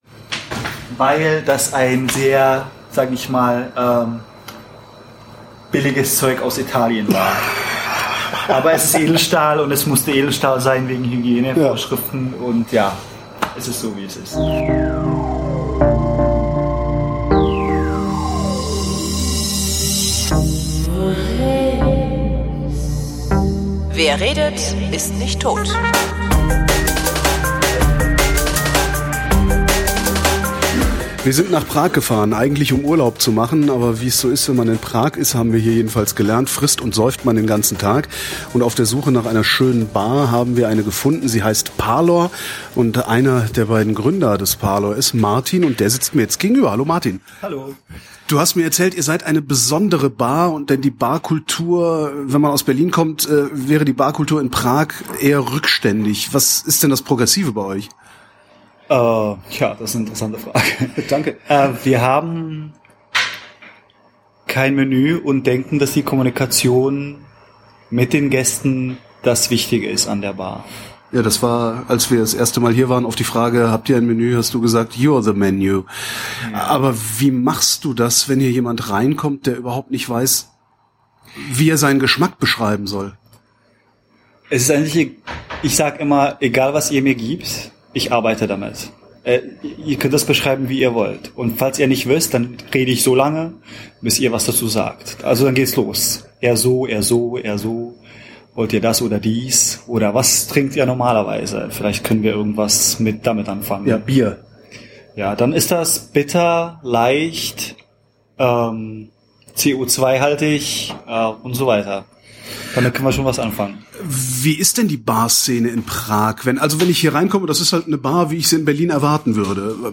mein Smartphone genommen